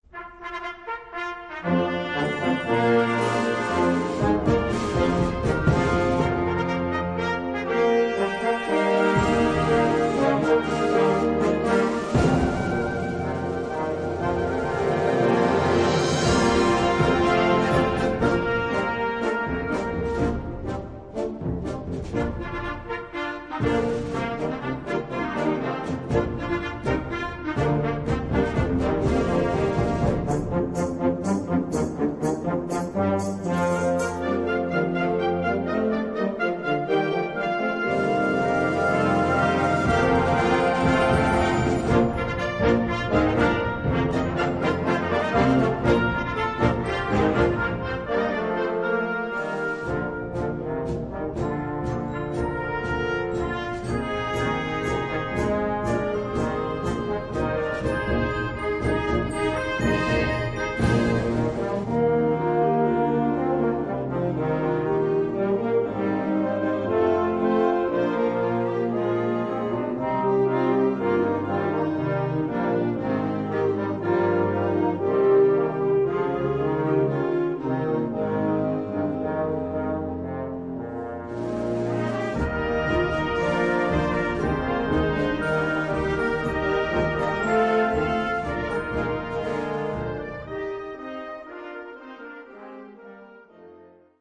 Gattung: Fantasie
Besetzung: Blasorchester